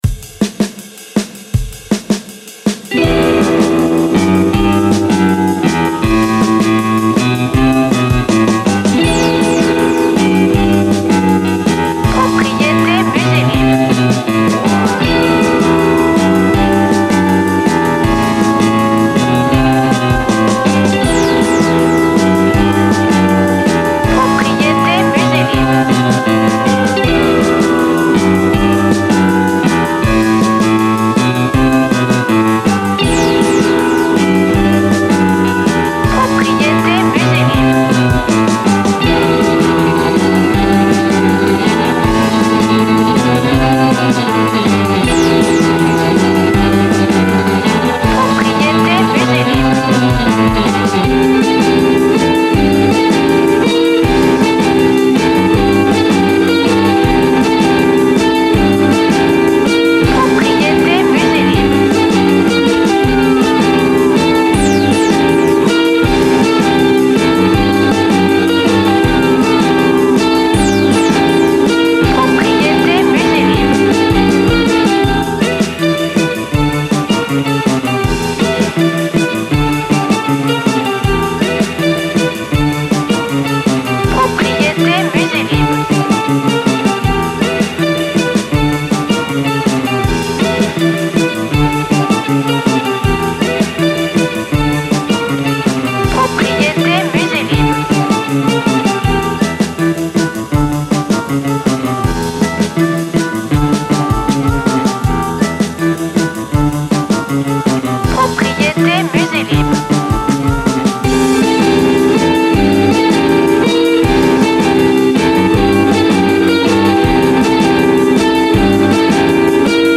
Surf psychedelic waves by listening to this early 60's song.